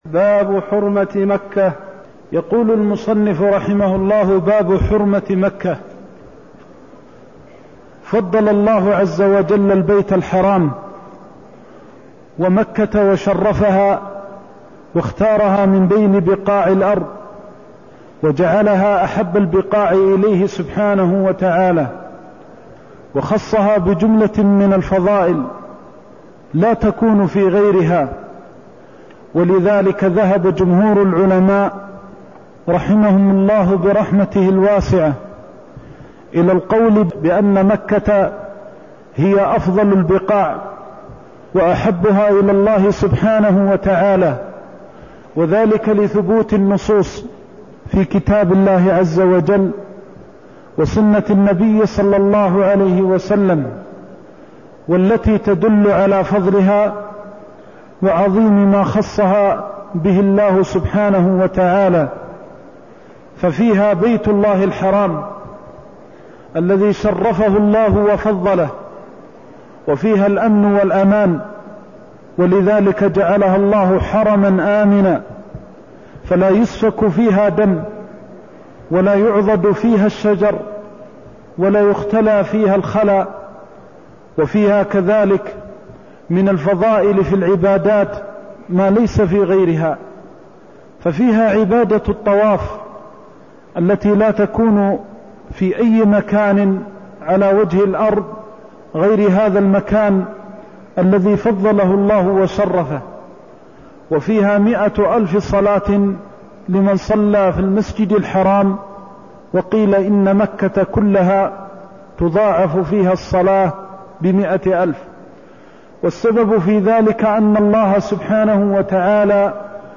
المكان: المسجد النبوي الشيخ: فضيلة الشيخ د. محمد بن محمد المختار فضيلة الشيخ د. محمد بن محمد المختار إن مكة حرمها الله تعالى يوم خلق السموات والأرض (209)